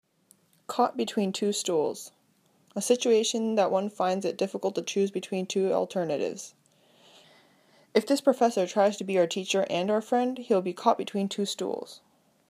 英語ネイティブによる発音は下記のリンクをクリックしてください。
caughtbetweentwostools.mp3